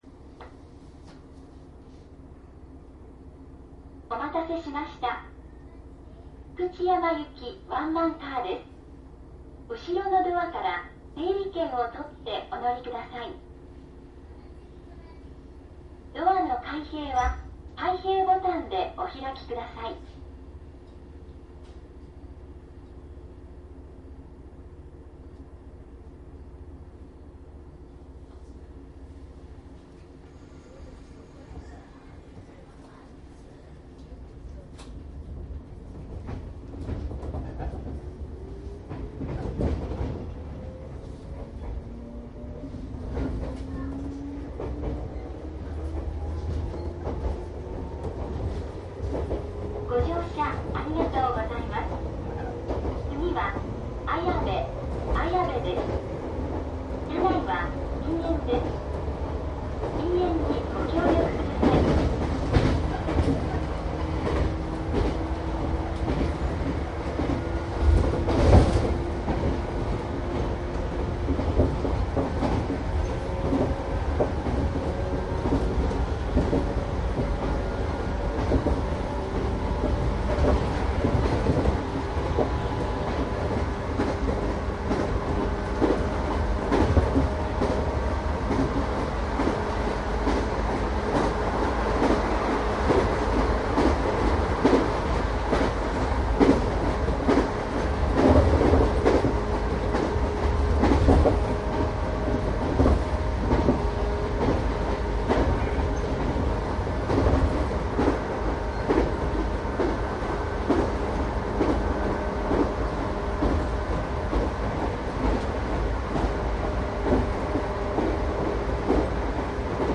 ♪JR西日本 山陰線 京都口 113系 走行音  ＣＤ♪
山陰線 京都口で113系を録音したCDです。
DATかMDの通常SPモードで録音（マイクＥＣＭ959）で、これを編集ソフトでＣＤに焼いたものです。